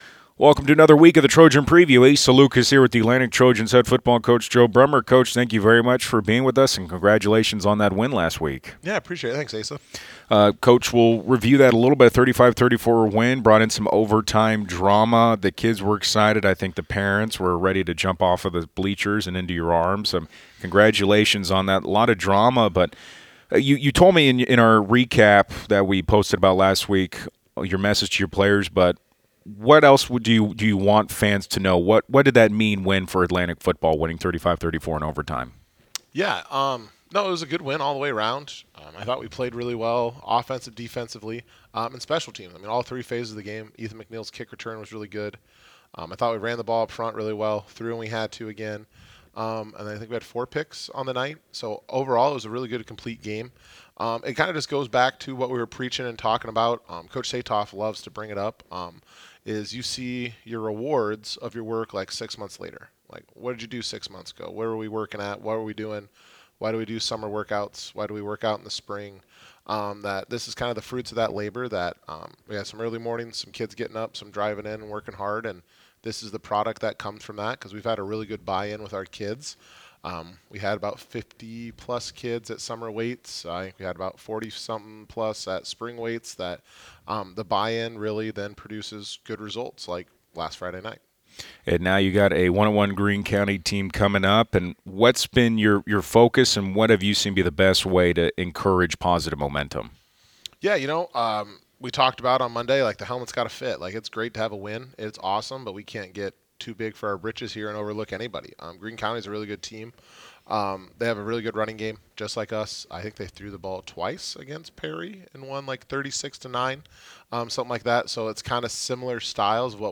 weekly discussion